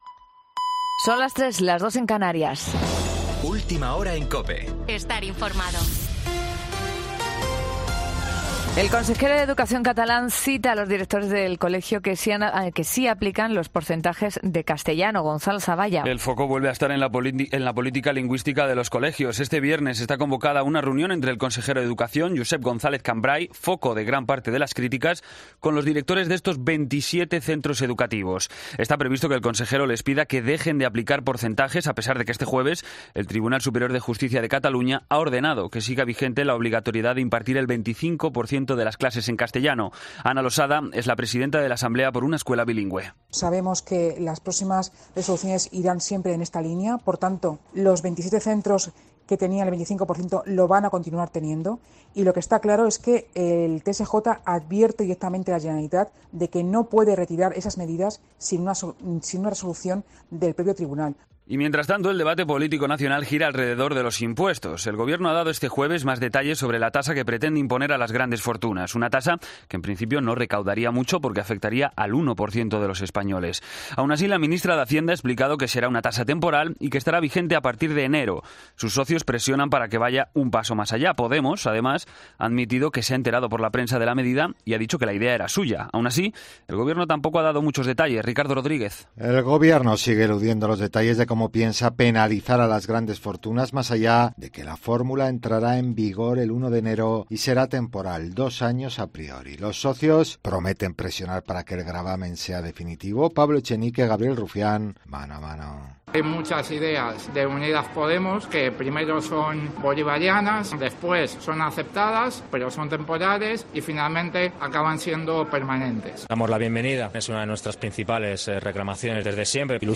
Boletín de noticias COPE del 23 de septiembre a las 03:00 hora
AUDIO: Actualización de noticias Herrera en COPE